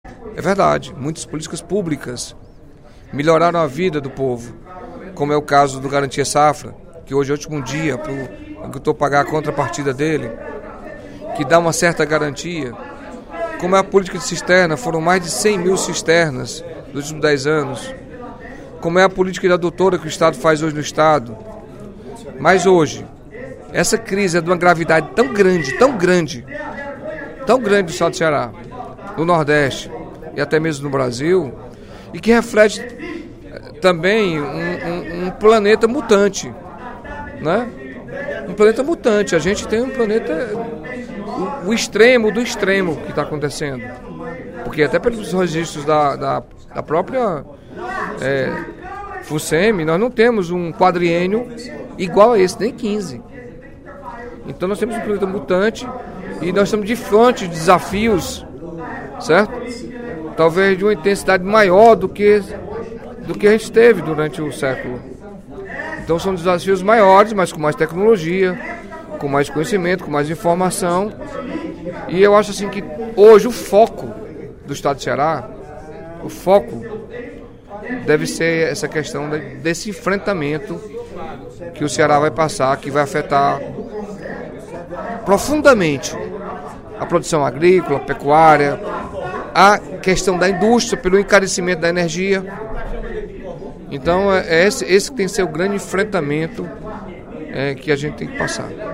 Em pronunciamento no primeiro expediente da sessão plenária desta sexta-feira (13/02), o deputado Carlos Felipe (PCdoB) disse que a seca deve ser pauta prioritária nas discussões do Parlamento.